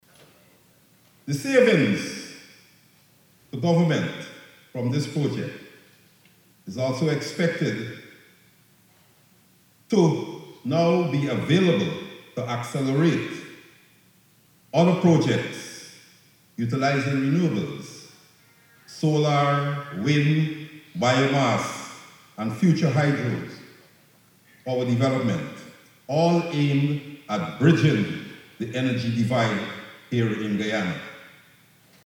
During the Guyana Energy Conference, Prime Minister Brigadier Mark Phillips highlighted the benefits of the Gas to Energy (GTE) project, emphasizing its role in reducing electricity costs, enhancing energy security, and fostering clean energy development in Guyana.